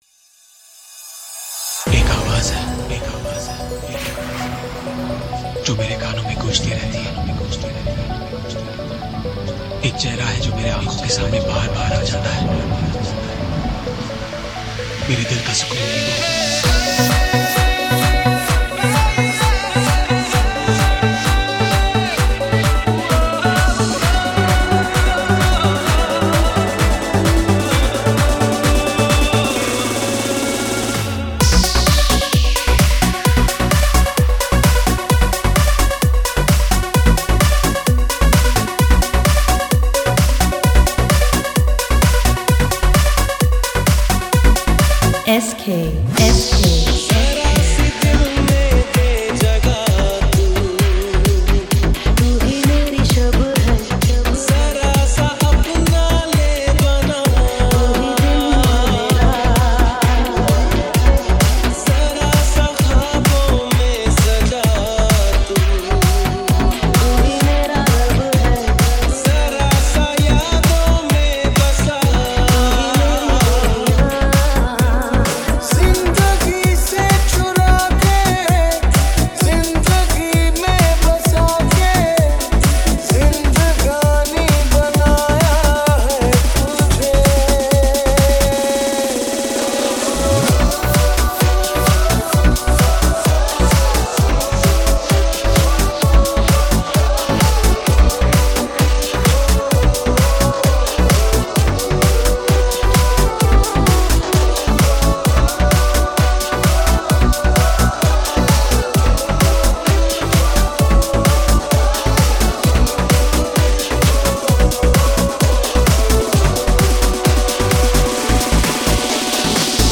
HomeMp3 Audio Songs > Others > Latest DJ-Mixes (March 2013)